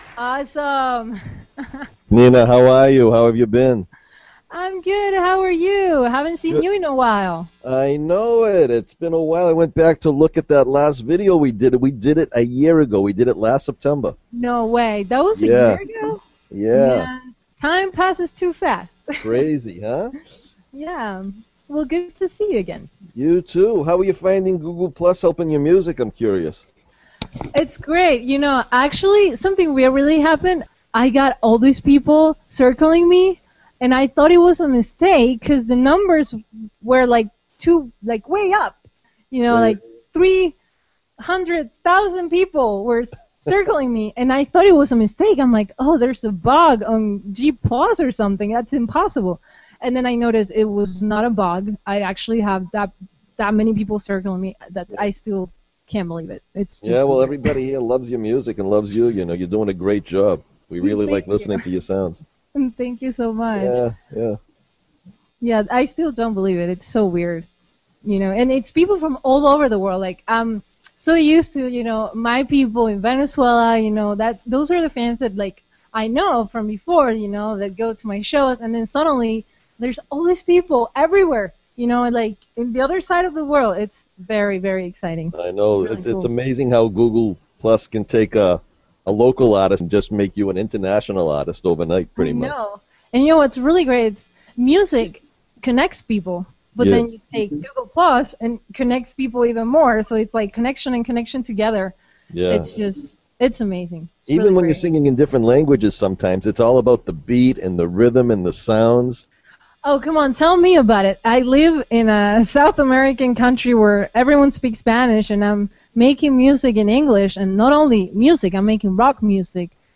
Here is a short piece of that interview.